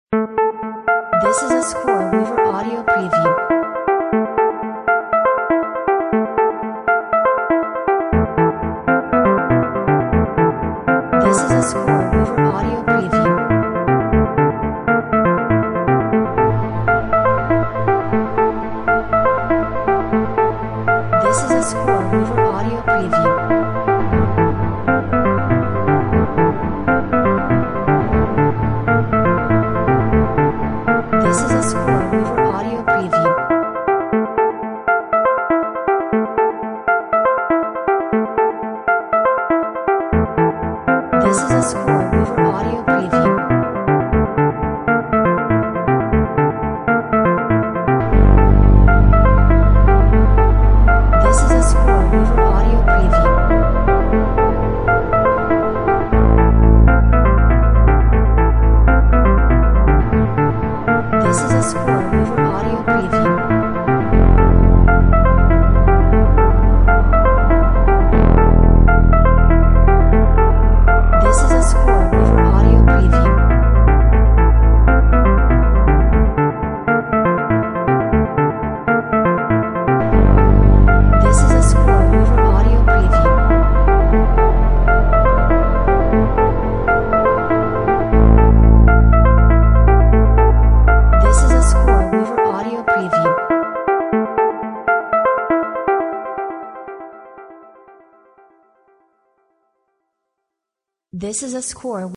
Melodic synth line with a retro sci-fi vibe!